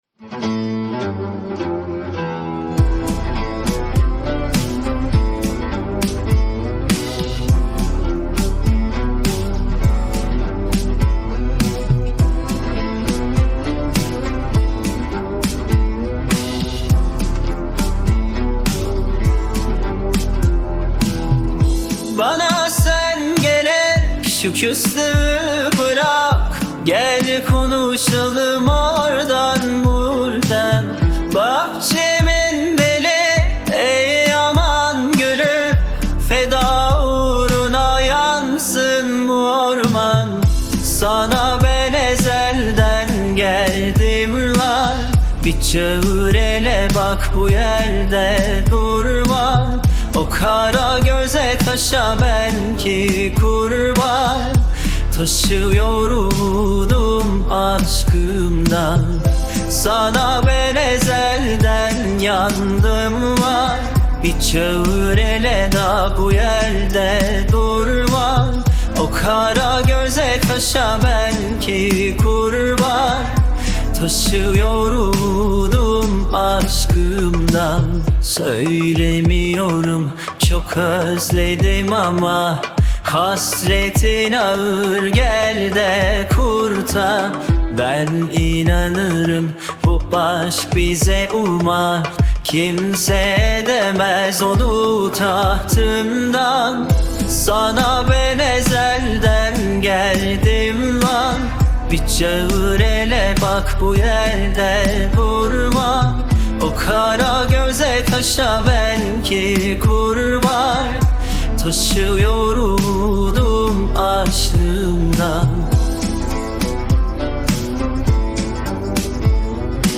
آهنگ ترکیه ای آهنگ غمگین ترکیه ای آهنگ هیت ترکیه ای